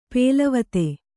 ♪ pēlavate